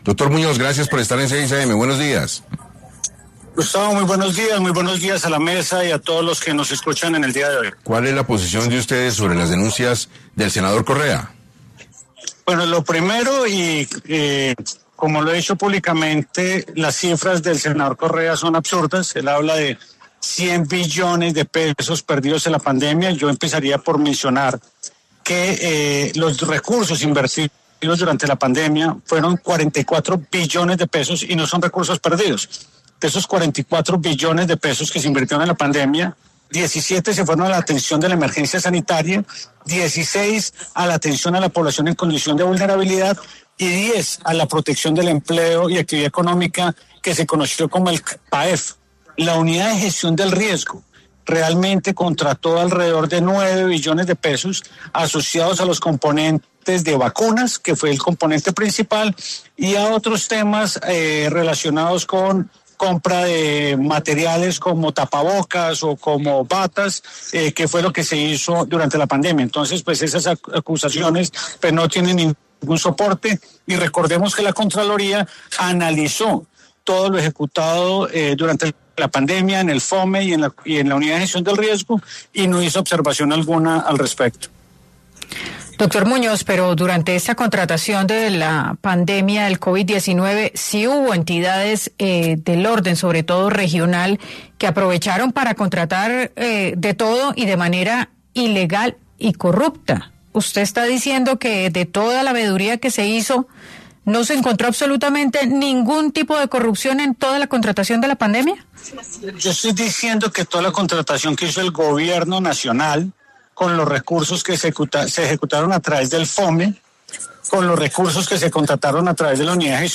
En 6AM de Caracol Radio estuvo Víctor Muñoz, exdirector del Dapre, para hablar sobre las denuncias que recibieron por parte del senador Correa y qué responde el gobierno del expresidente Iván Duque ante la denuncia de la supuesta pérdida de $100 billones durante la pandemia.